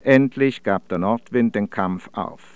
Sprachaufnahme (aus den IPA-Standard-Beispielen für Deutsch